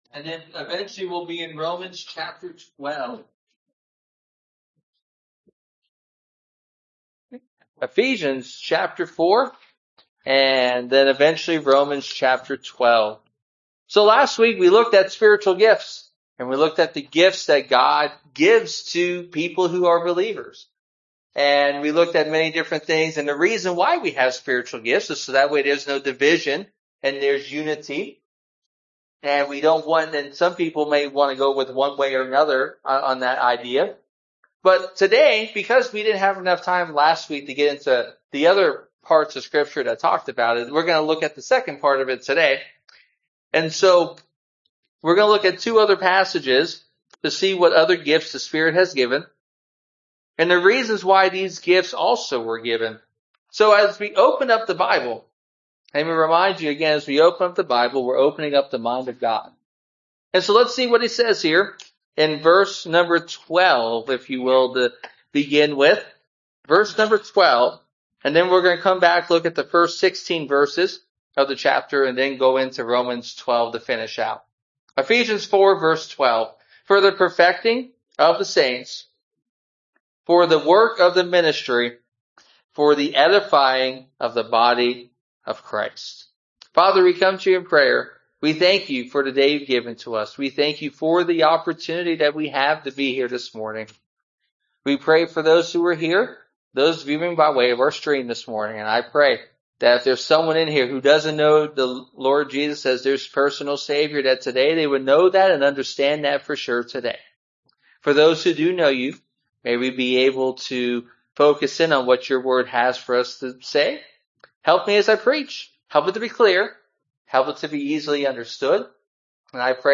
Service Type: Sunday Morning (voice only)